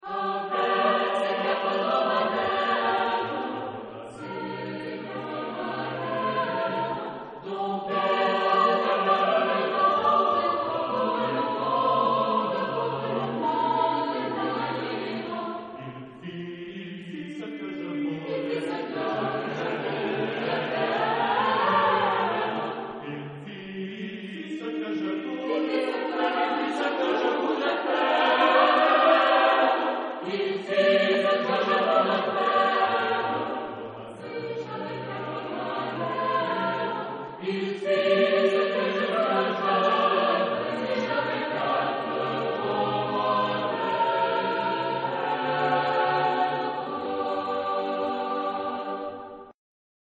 Genre-Style-Form: Partsong ; Secular ; Contemporary
Mood of the piece: subtle ; joyous ; light
Type of Choir: SATB  (4 mixed voices )
Tonality: G minor